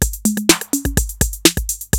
TR-808 LOOP1 4.wav